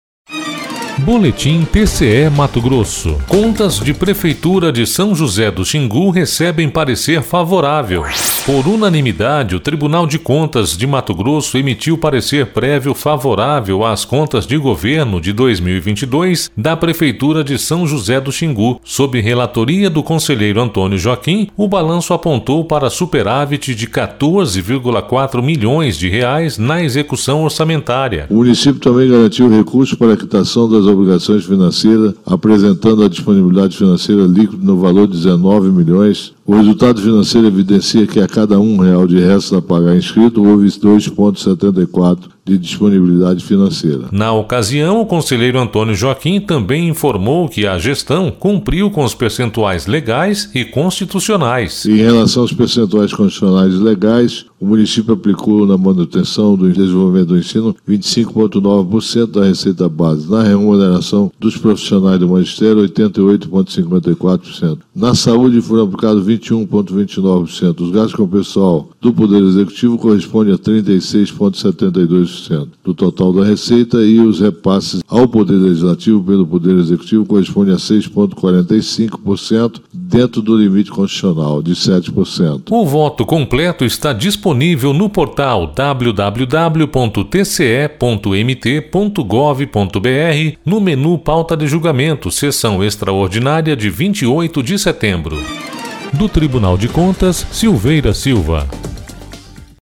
Sonora: Antonio Joaquim – conselheiro do TCE-MT